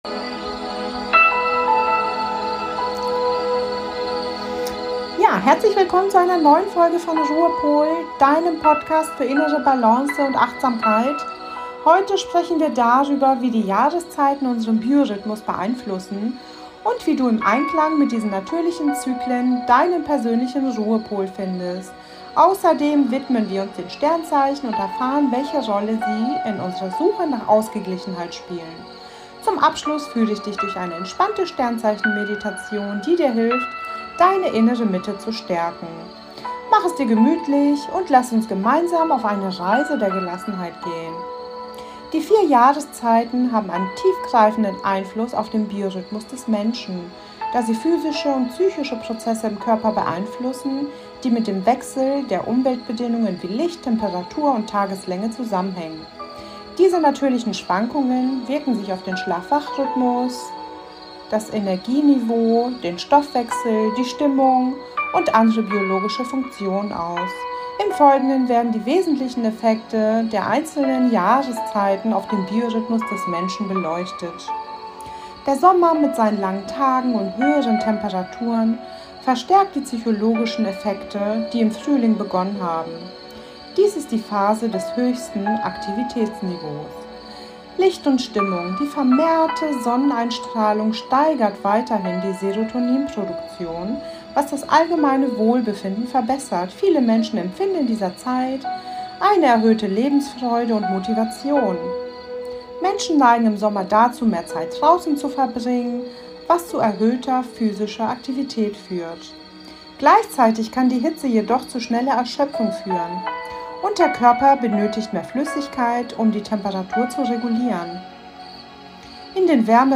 Heute sprechen Wir darüber, wie die Jahreszeiten unseren Biorhythmus beeinflussen und wie du im Einklang mit diesen natürlichen Zyklen deinen persönlichen Ruhepol findest. Außerdem widmen wir uns den Sternzeichen und erfahren, welche Rolle sie in unserer Suche nach Ausgeglichenheit spielen. Zum Abschluss führe ich dich durch eine entspannende Sternzeichen-Meditation, die dir hilft, deine innere Mitte zu stärken.